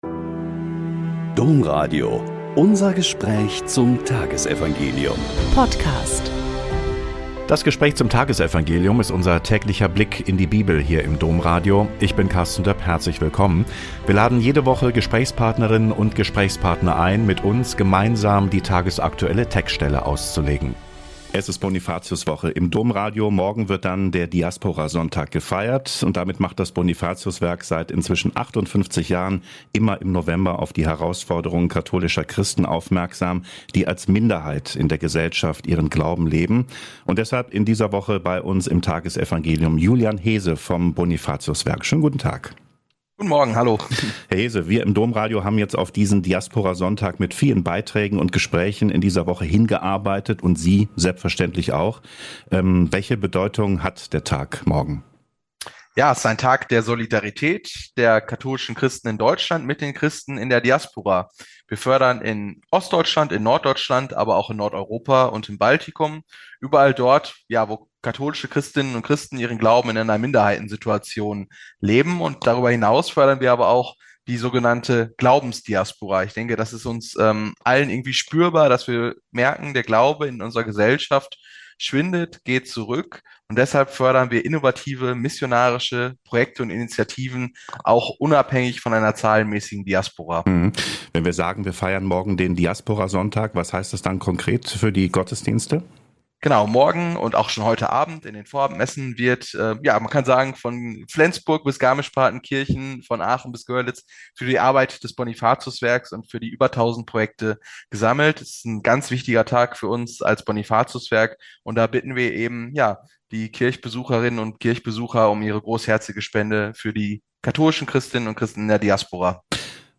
Lk 18,1-8 - Gespräch